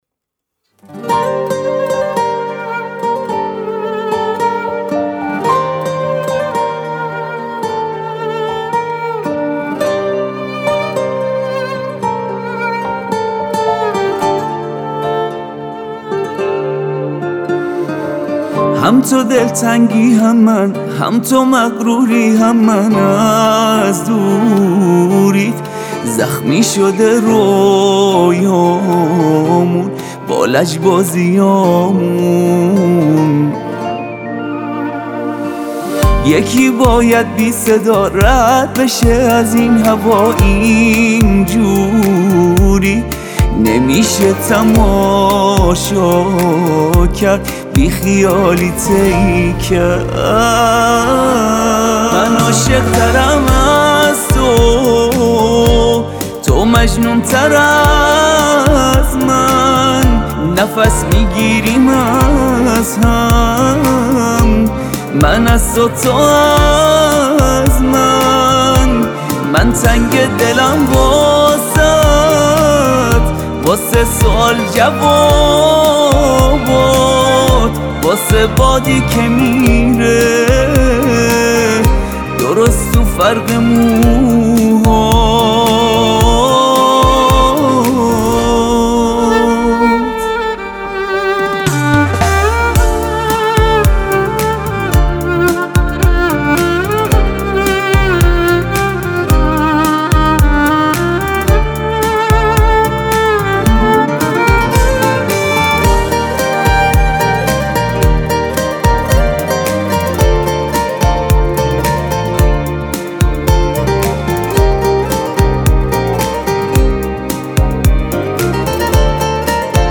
پاپ
آهنگ با صدای زن